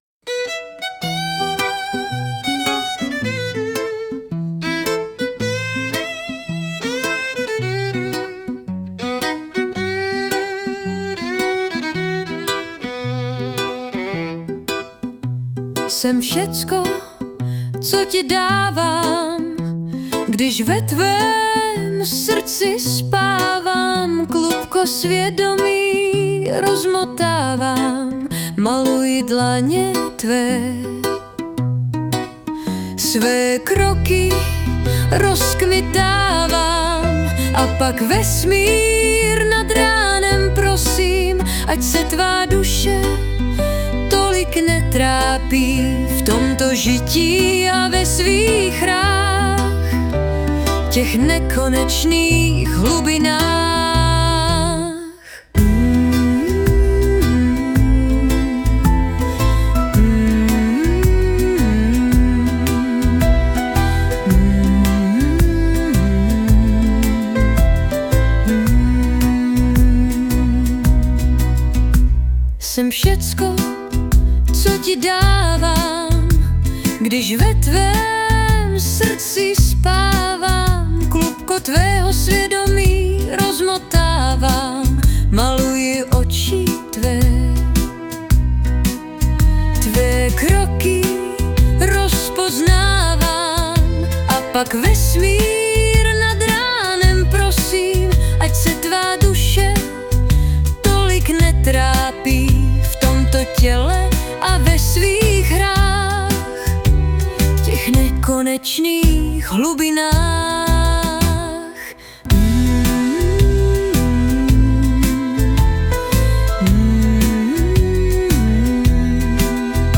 hudba a zpěv: AI (by SUNO)
dle mého bombasticky - já se tu kochám - a srdce zpívá s ní!!
a ty housle - wow :)**